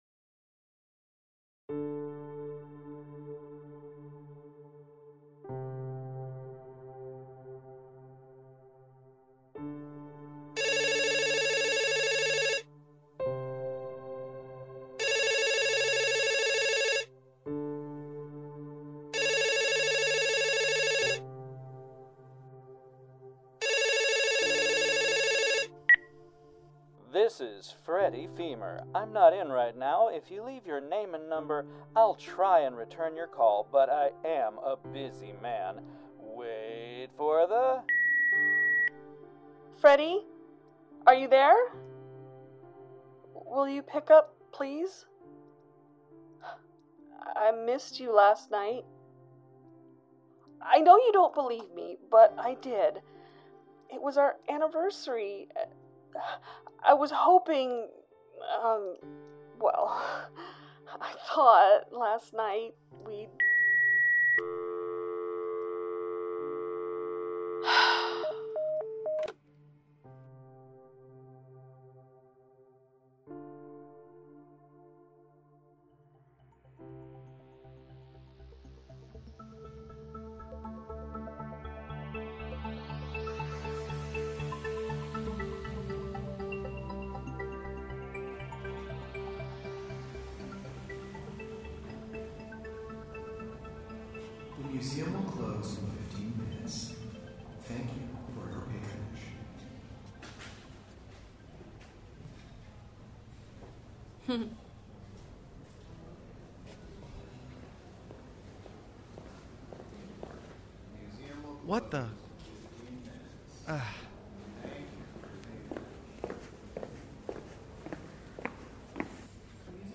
Our adaptation of Terry Moore’s Classic continues. In this one, meet David, Francine surprises Freddie about as much as Freddie surprises Francine and tremble before the eruption of Mount Katchoo!